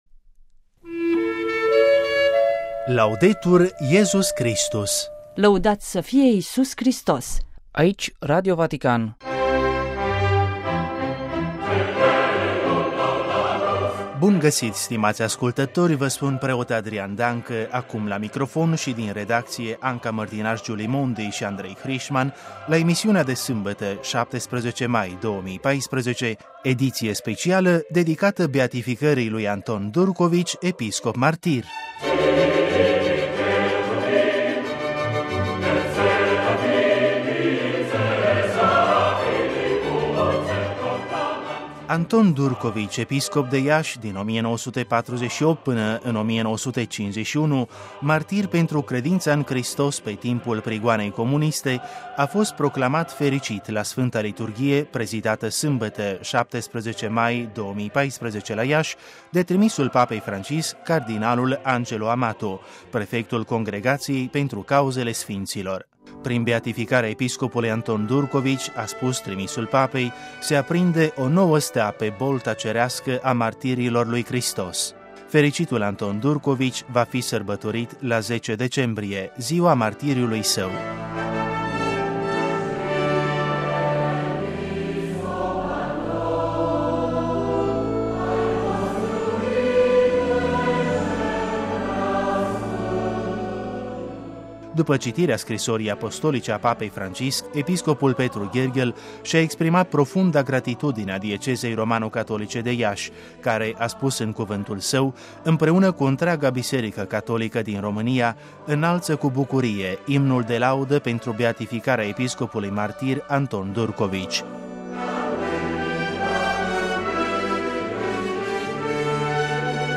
Prin telefon, din vechea capitală m